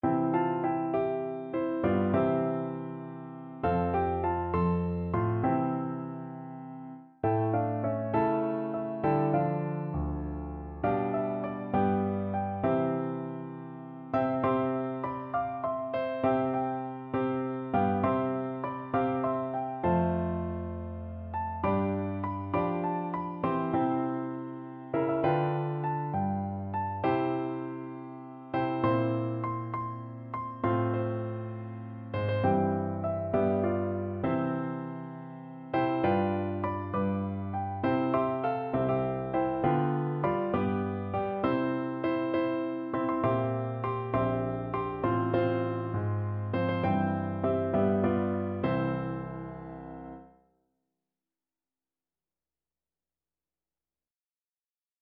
6/8 (View more 6/8 Music)
Piano Duet  (View more Intermediate Piano Duet Music)
Classical (View more Classical Piano Duet Music)